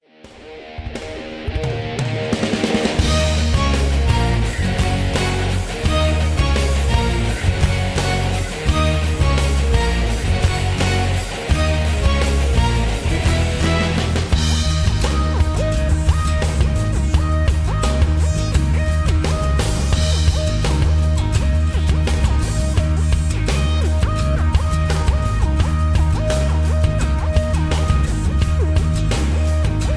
mp3 backing tracks
rock and roll